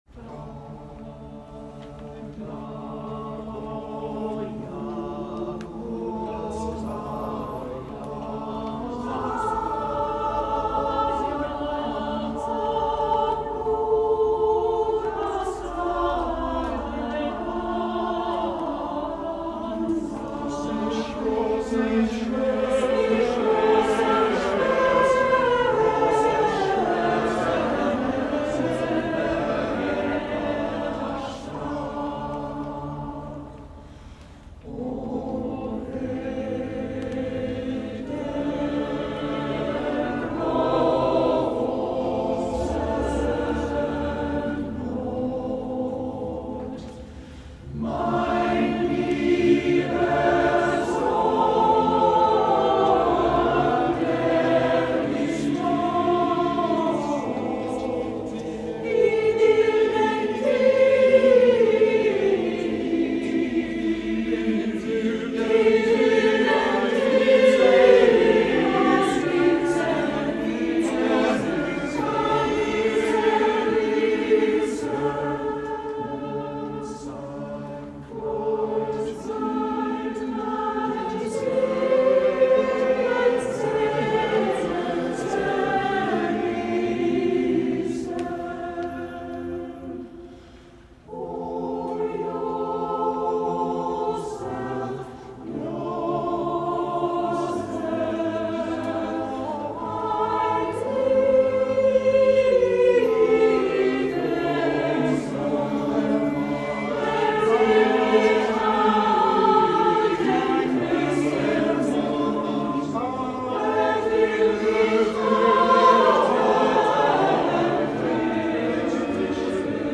performed at the annual Loft Concert